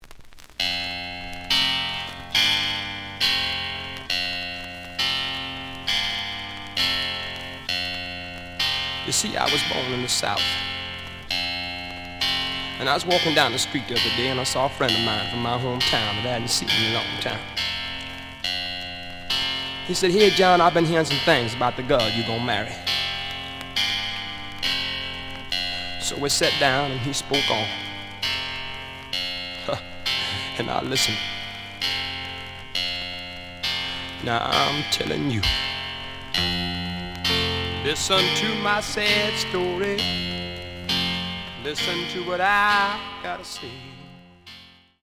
The audio sample is recorded from the actual item.
●Genre: Rock / Pop
Looks good, but some noise on B side.)